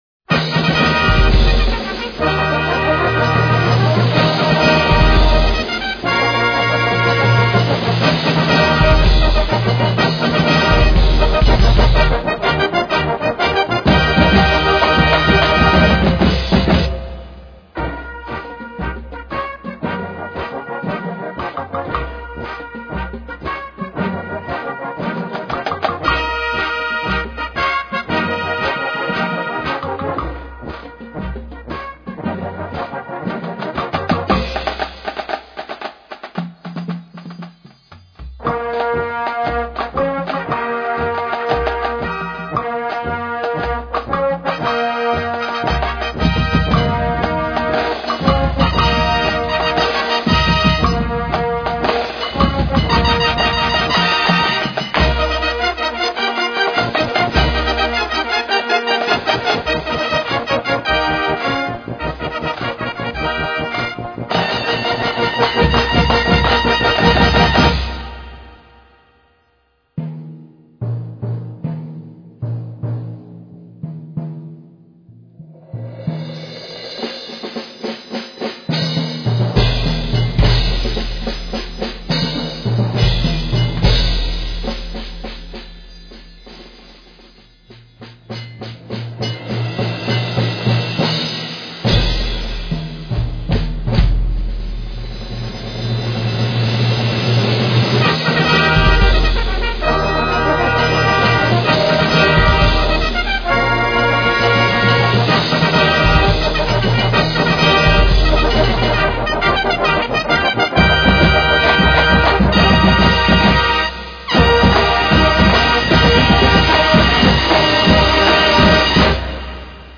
Besetzung: Naturtonfarenzug.
Musik für Naturton-Fanfarenzüge